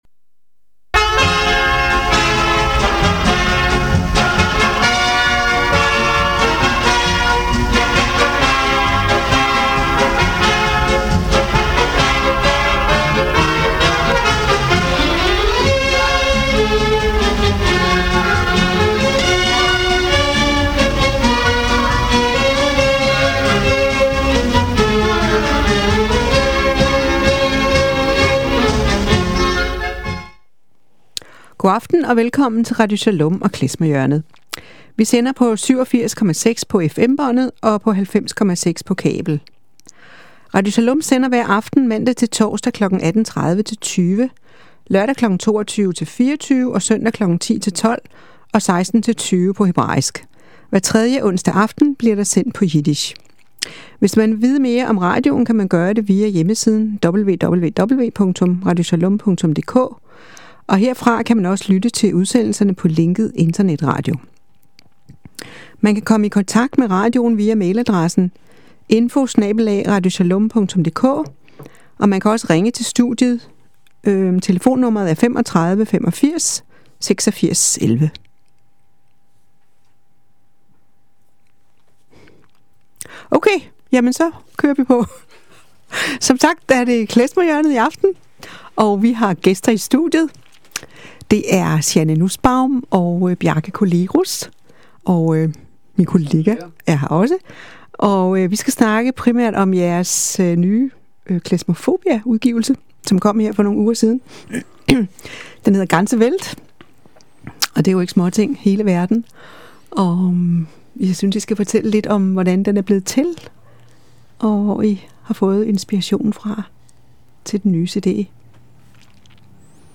Beskrivelse: Klezmer hjørne og interview